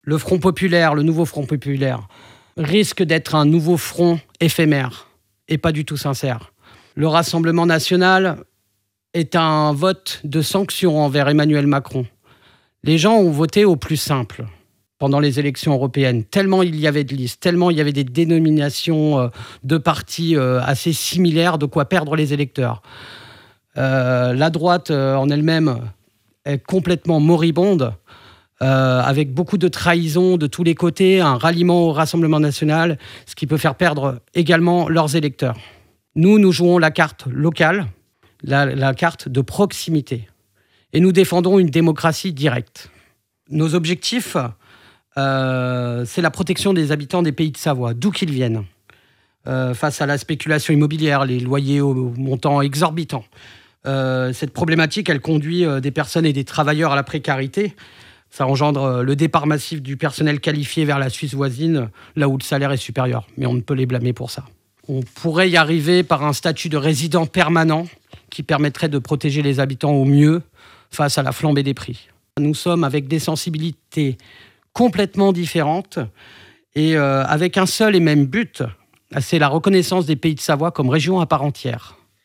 Voic les interviews des 8 candidats de cette 2eme circonscription de Haute-Savoie (par ordre du tirage officiel de la Préfecture) :